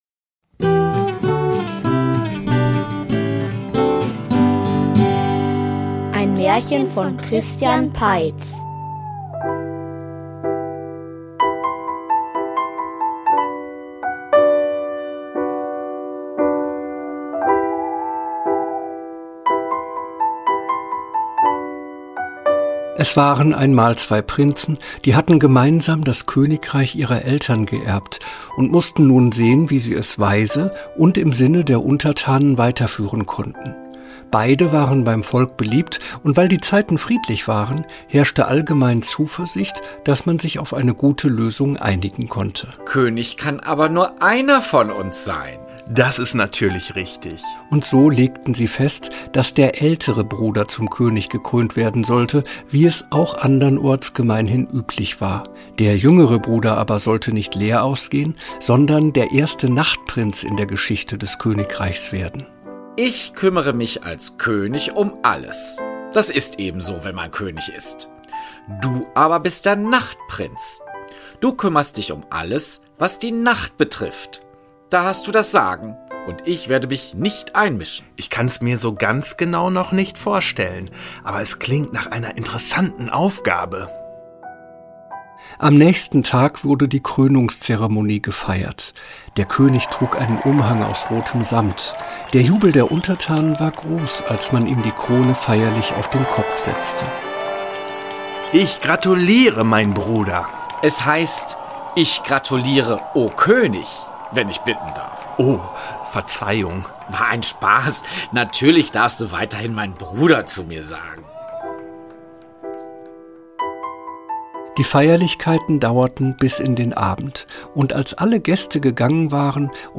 Der König macht seinen Bruder zum Nachtprinzen, der nun alles entscheiden soll, was die Nacht betrifft. Doch auch andere sind in der Nacht tätig: Der Nachtwächter, ein Dichter und ein sonderbarer Forscher. ... Dieses Hörspiel wirft einen märchenhaft-verträumten Blick auf die Nacht.